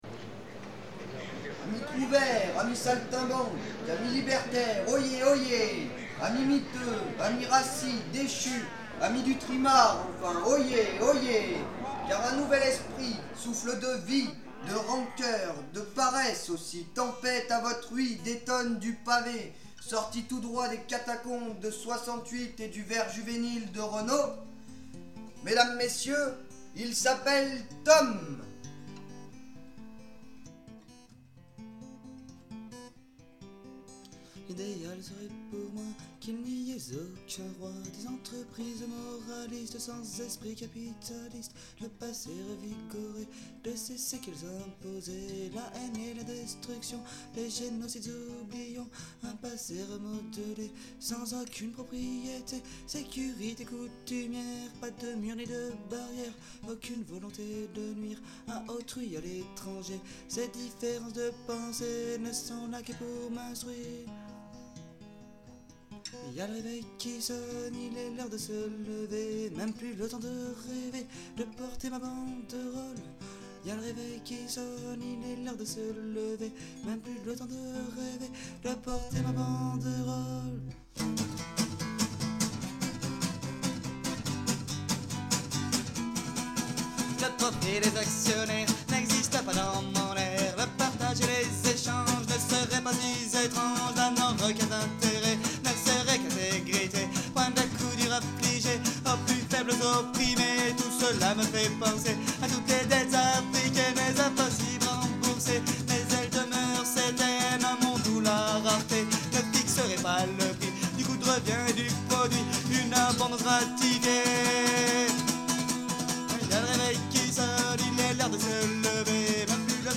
Un slam radiophonique